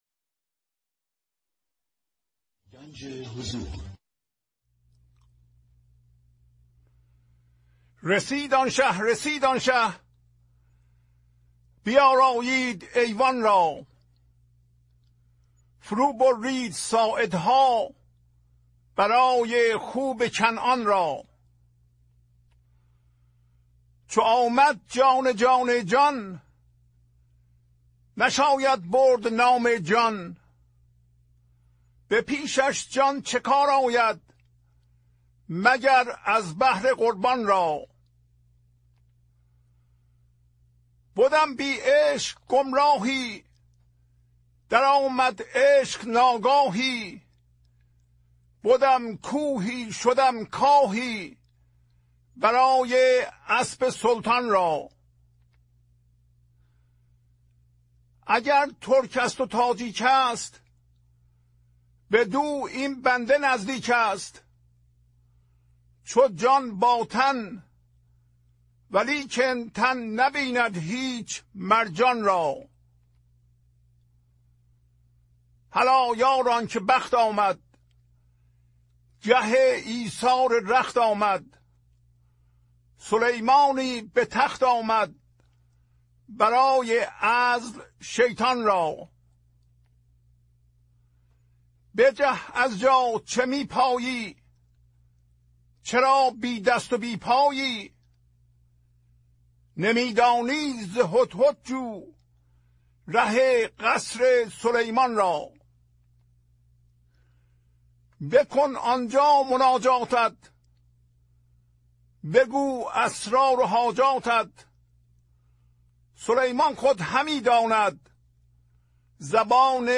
خوانش تمام ابیات این برنامه - فایل صوتی
1016-Poems-Voice.mp3